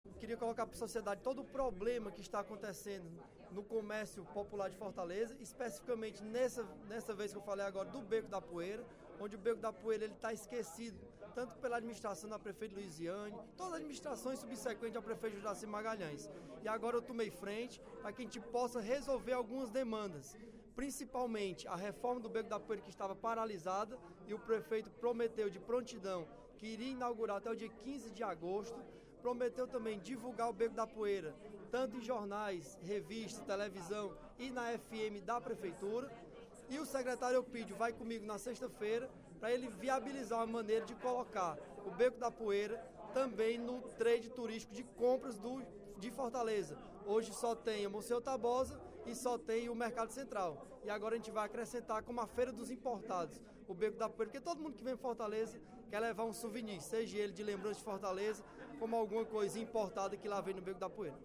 O deputado Bruno Gonçalves (PEN) relatou, no primeiro expediente da sessão plenária desta terça-feira (16/06), o encontro com o prefeito de Fortaleza, Roberto Cláudio, para apresentar as principais demandas dos comerciantes do Beco da Poeira.